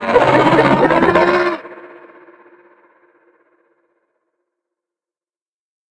File:Sfx creature pinnacarid callout 04.ogg - Subnautica Wiki